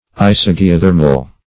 isogeothermal.mp3